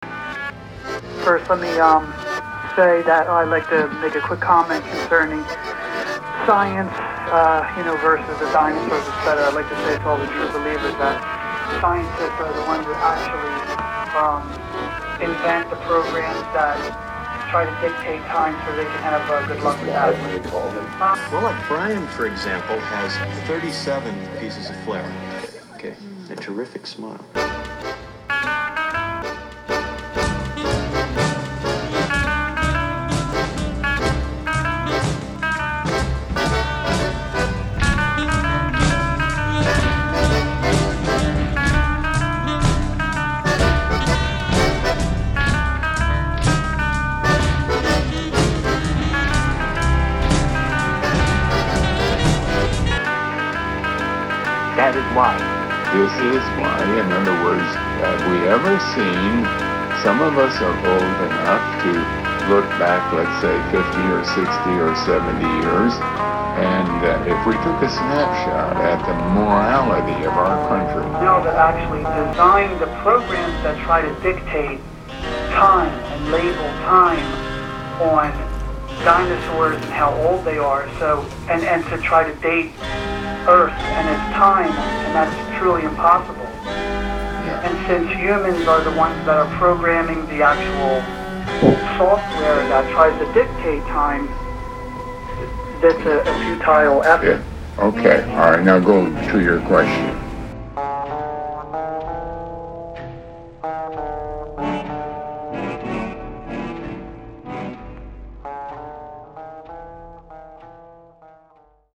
collage music